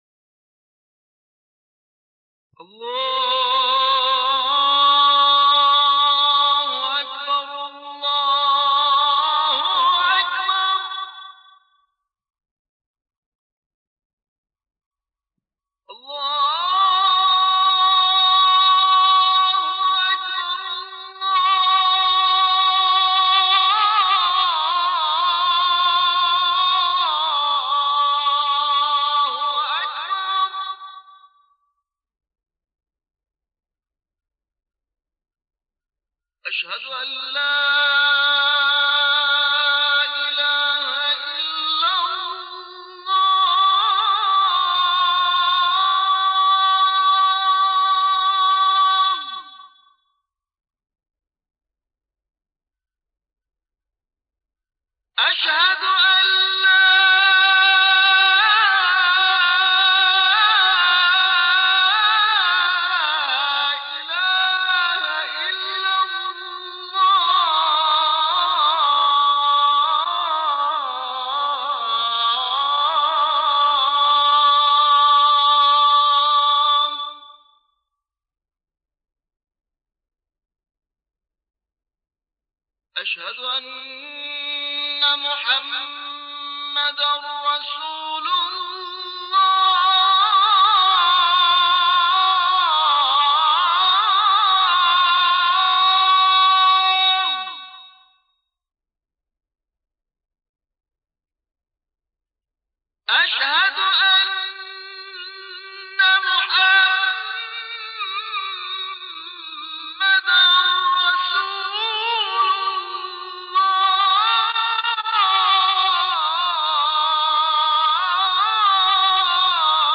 اذان